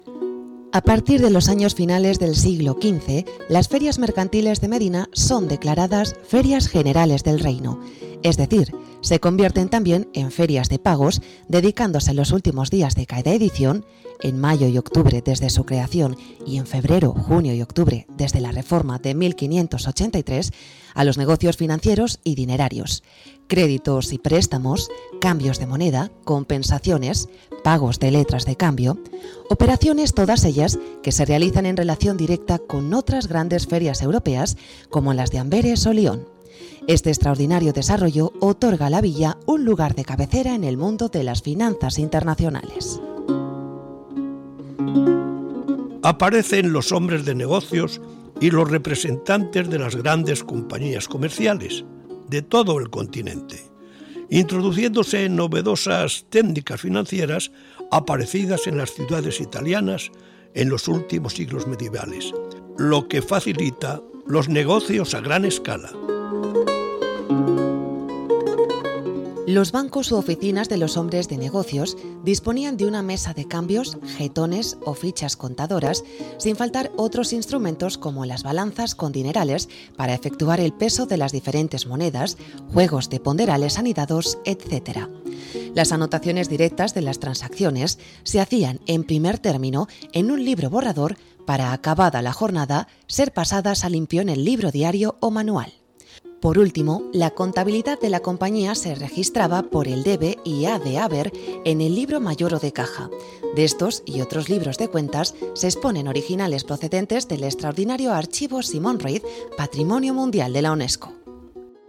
Locuciones generales de capítulos con documentos del Archivo Simón Ruiz